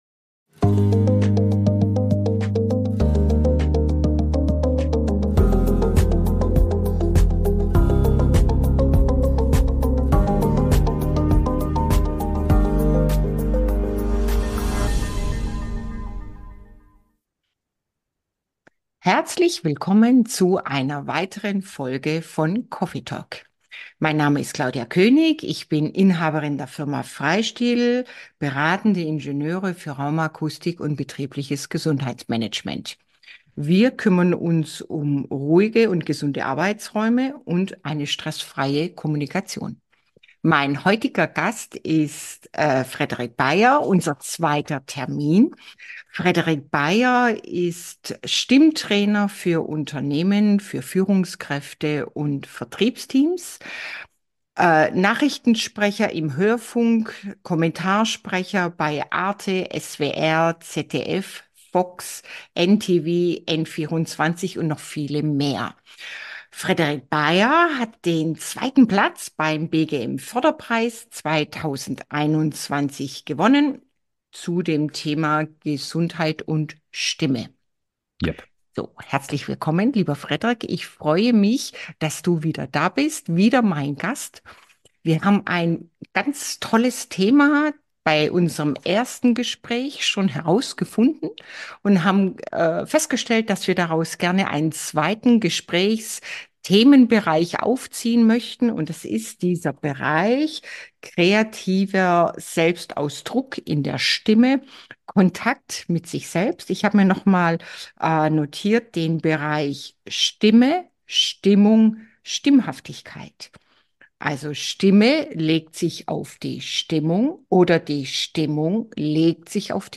In dieser spannenden Folge von Coffee Talk speche ich